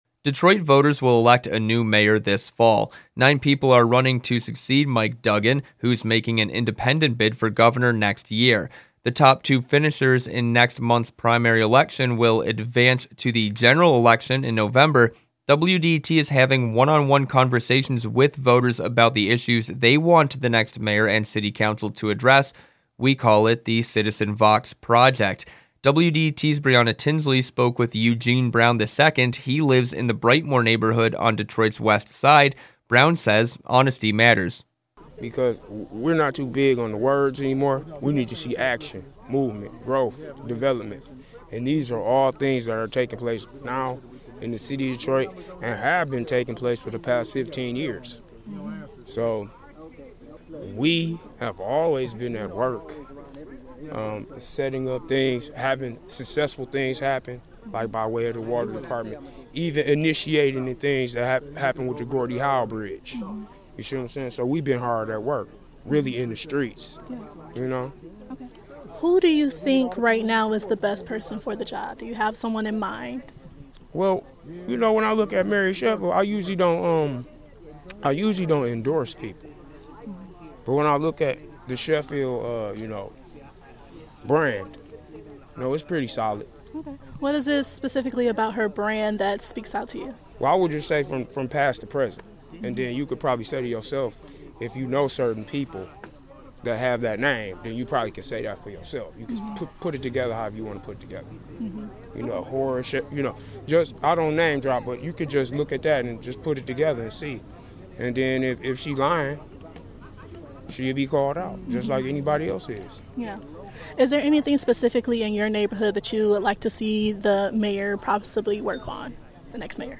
WDET is having one-on-one conversations with voters about the issues they want the next mayor and city council to address.